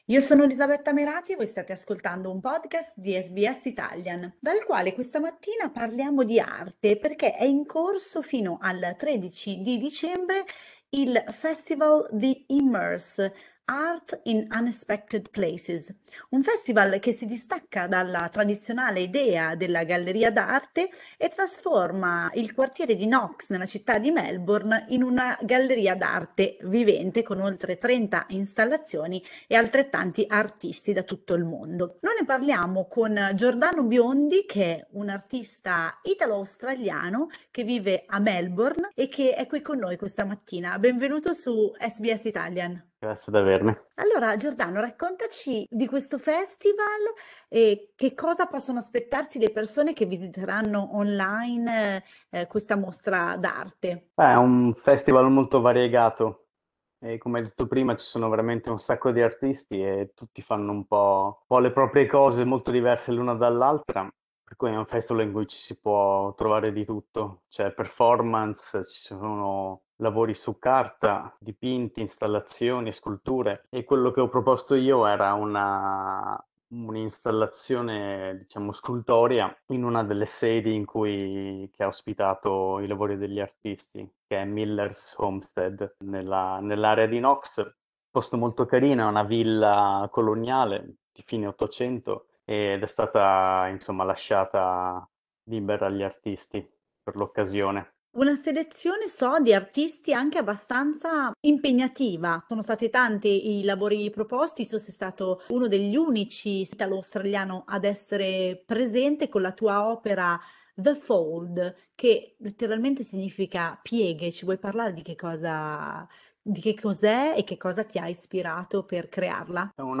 Ascolta l'intervista completa: LISTEN TO Arte contemporanea a Melbourne, un italiano fra gli artisti in mostra SBS Italian 09:33 Italian Le persone in Australia devono stare ad almeno 1,5 metri di distanza dagli altri.